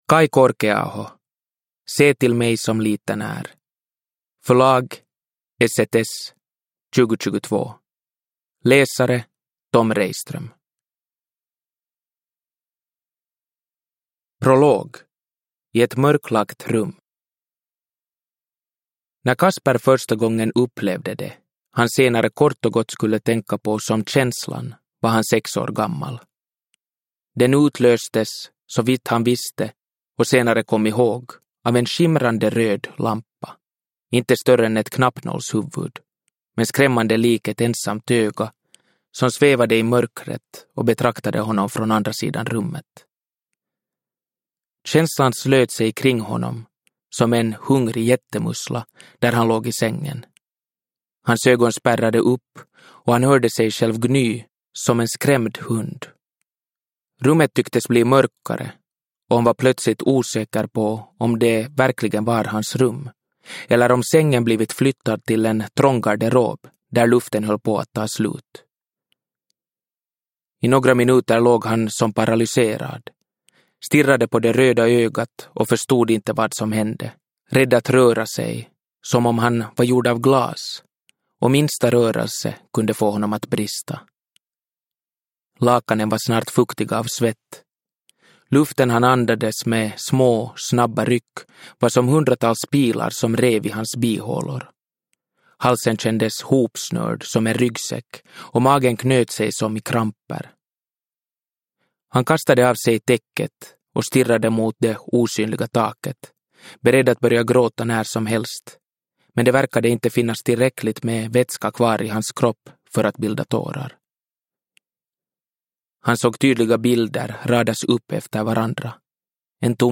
Se till mig som liten är – Ljudbok – Laddas ner